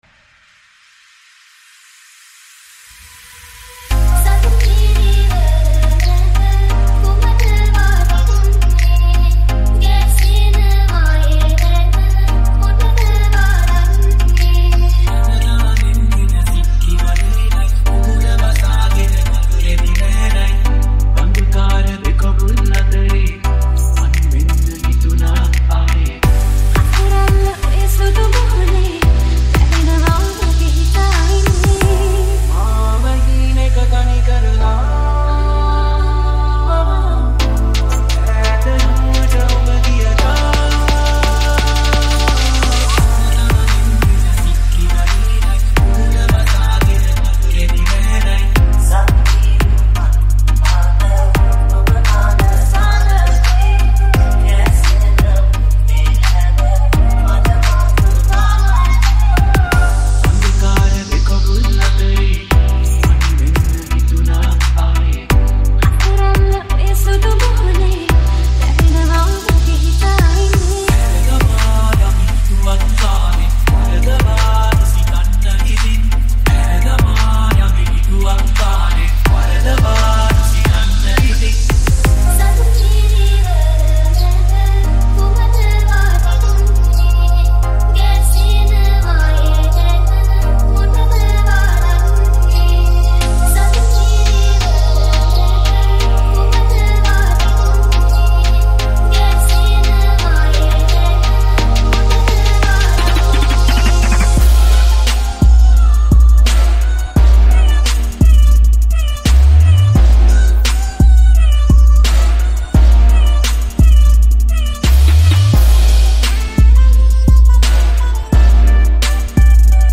Sinhala Dj , Sinhala Mashup
EDm Remix